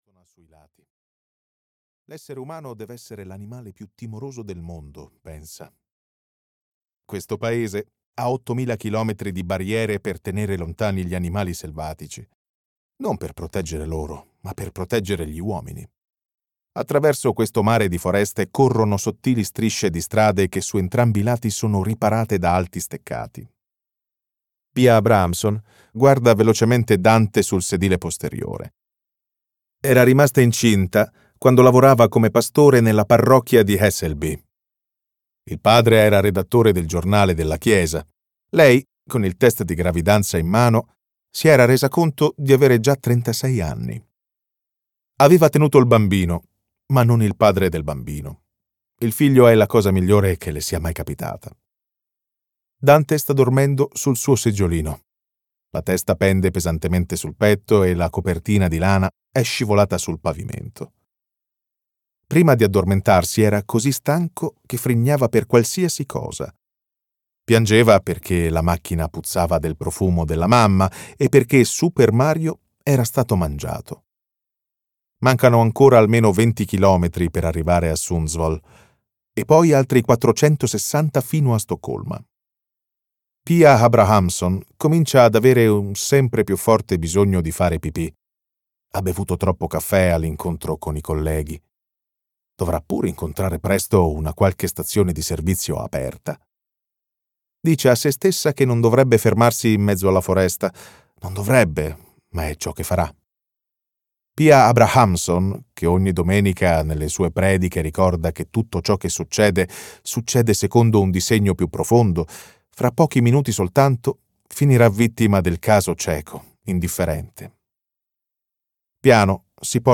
"La testimone del fuoco" di Lars Kepler - Audiolibro digitale - AUDIOLIBRI LIQUIDI - Il Libraio